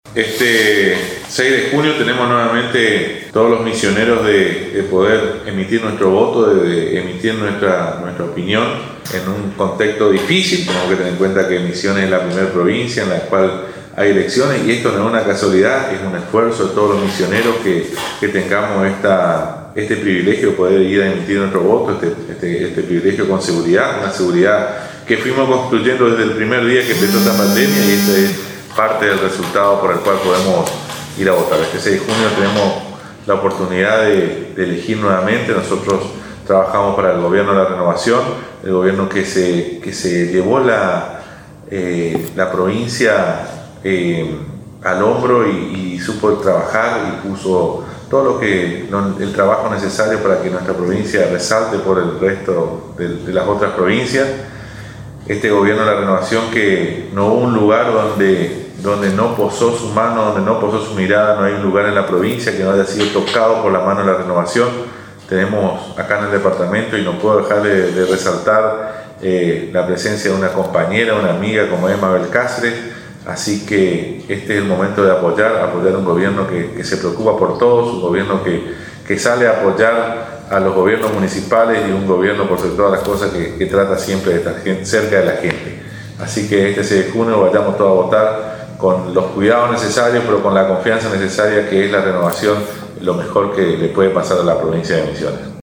Mensaje de Javier Peralta, Intendente de San Ignacio, para este 6 de junio - Agencia de Noticias Guacurari